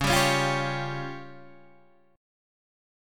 C# Major Flat 5th